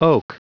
Prononciation du mot oak en anglais (fichier audio)
Prononciation du mot : oak